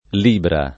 l&bra] s. f. (poet. «bilancia») — con L‑ maiusc. come nome di costellazione (= Bilancia): Di Vergine valica in Libra L’amico dell’opere, il Sole [di v%rJine v#lika in l&bra l am&ko dell 0pere, il S1le] (D’Annunzio) — sim. il cogn. Libra — anche variante ant. di libbra («misura di peso») e di lira («moneta; tributo»)